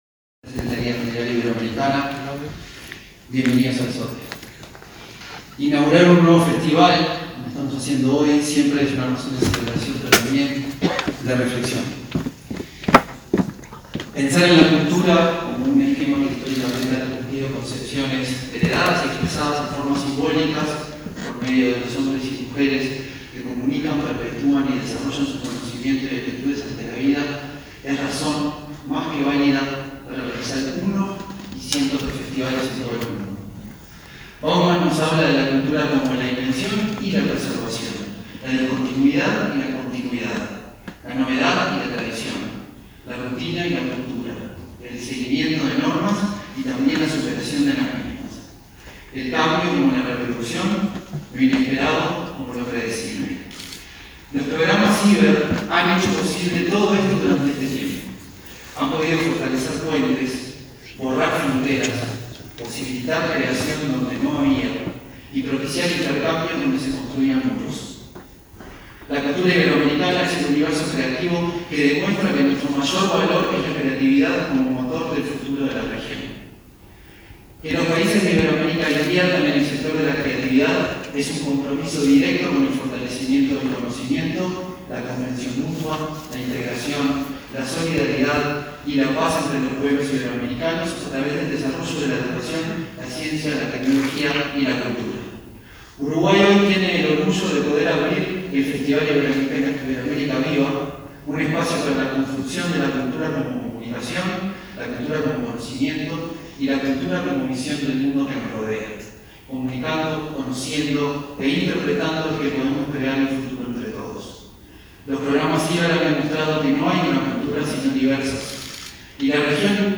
Conferencia de prensa de autoridades de gobierno por el festival “Iberoamérica viva”
En el marco de los 30 años de las Cumbres Iberoamericanas de Jefes de Estado y de Gobierno, se realizó, este 22 de agosto, el festival “Iberoamérica viva”. Participaron la vicepresidenta de la República, Beatriz Argimón; el ministro de Educación y Cultura, Pablo Da Slveira, y el presidente del Sodre, Martín Inthamoussu.
conferencia.mp3